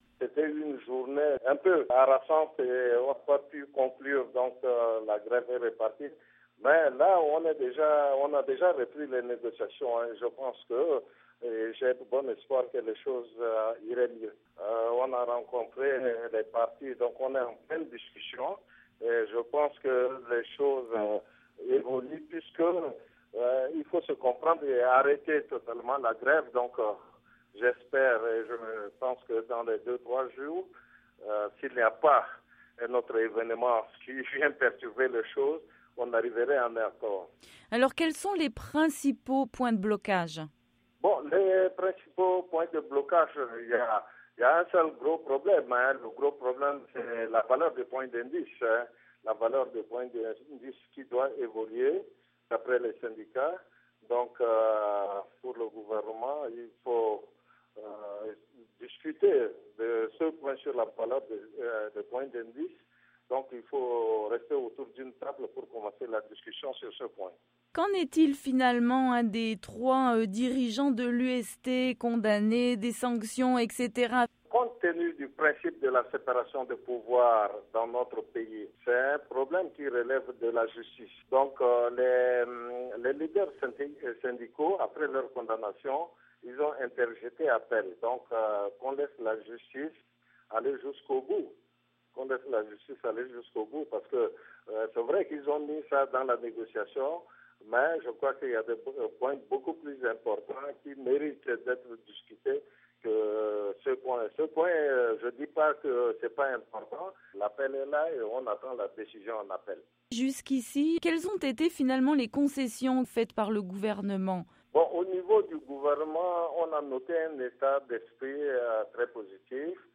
Le président du Comité National de Dialogue Social Tedji Mbainaissem